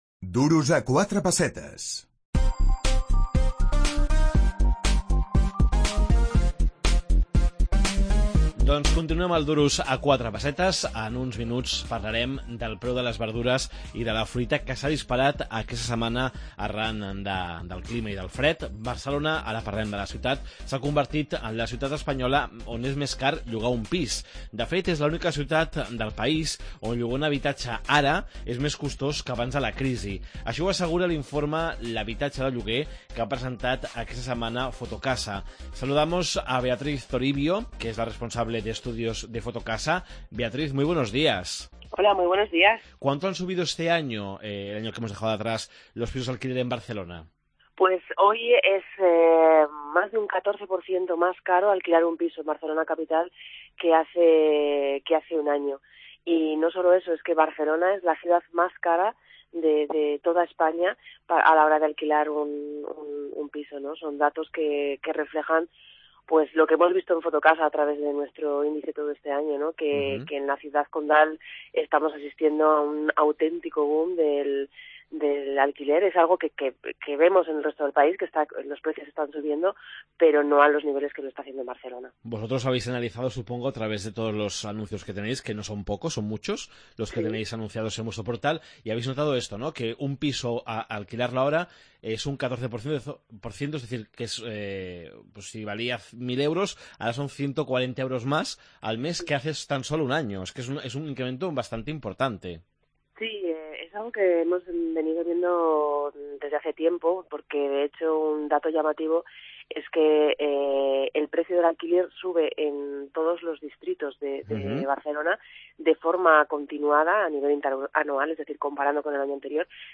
Barcelona és la ciutat espanyola on és més car llogar un habitatge. Ciutat Vella és el barri més car de tot Espanya. Entrevista